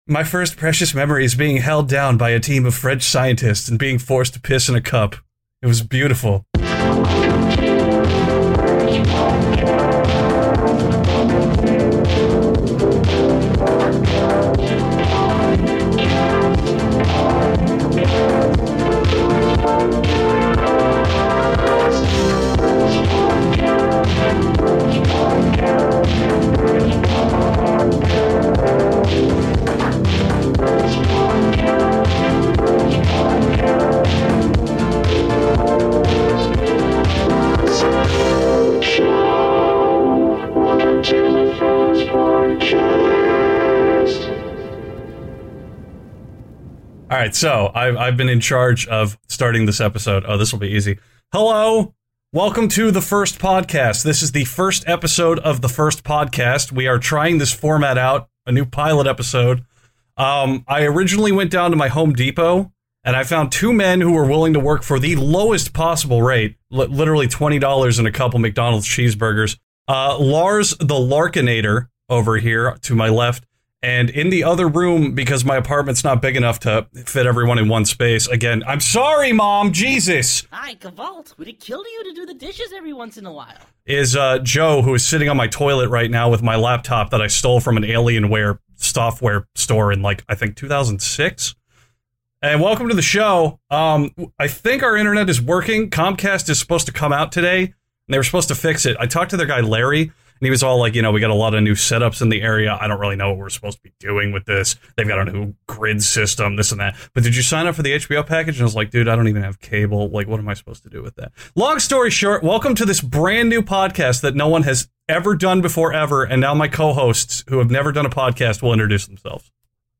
This episode was recorded live on our Discord.